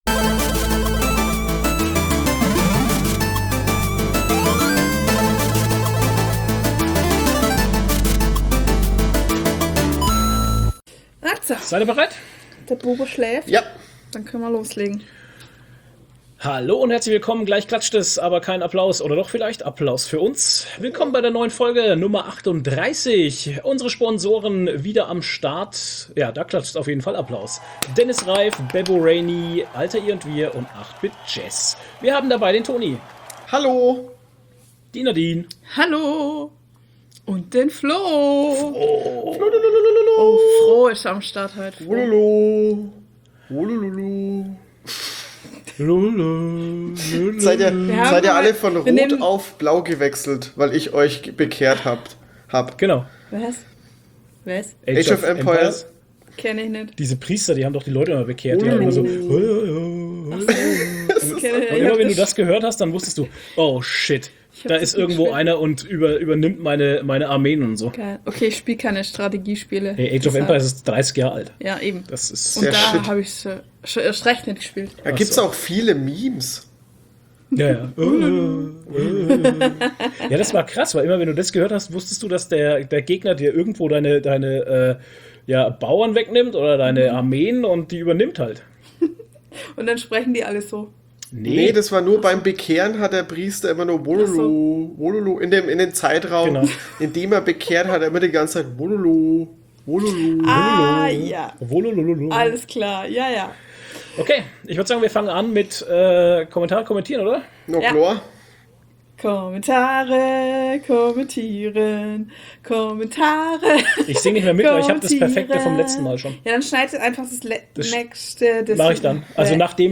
Viel Spaß beim Zuhören, wie ganz normale Menschen von ihrem Leben erzählen.